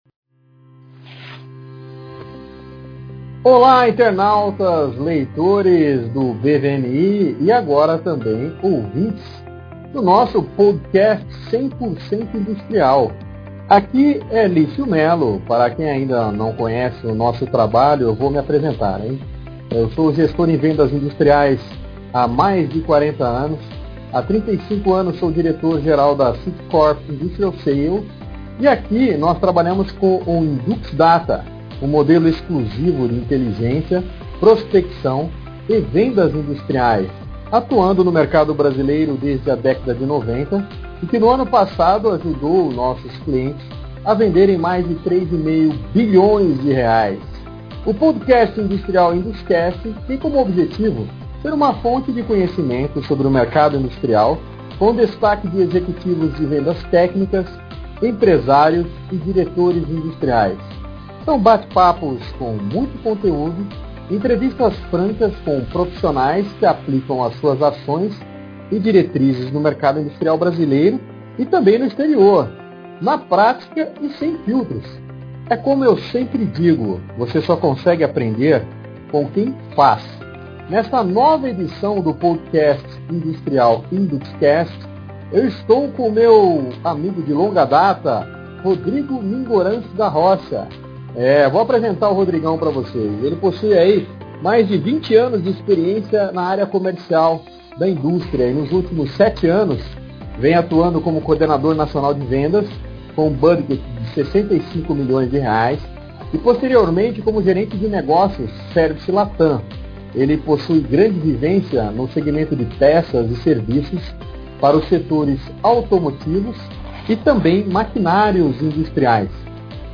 InduXCast – Entrevista
Esta edição vem com uma conversa franca sobre os desafios profissionais na indústria, residir no exterior, muito sobre gestão comercial 100% industrial, saias justas, desafios, erros, acertos e muito conteúdo prático, como sempre.